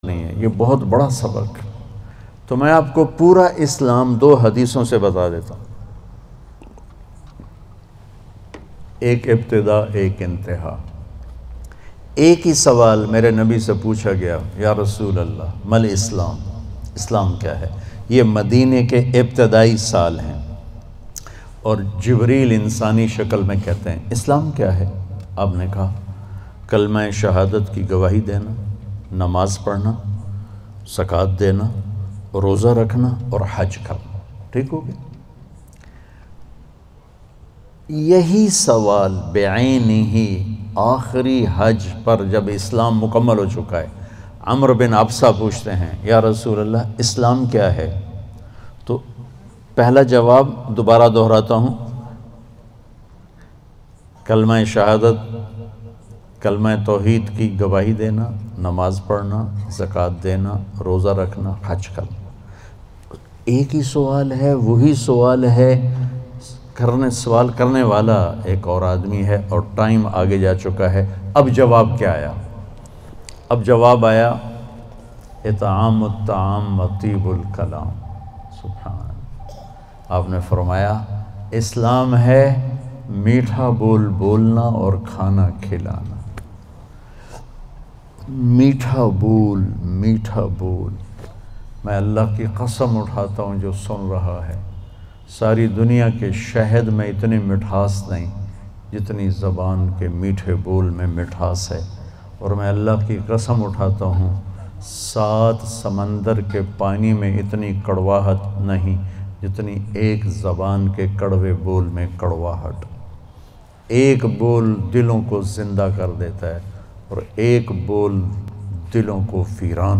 Listen online and download mp3 audio bayan Zindagi Guzarne Ka Behtareen Tarika in the voice of Maulana Tariq Jameel.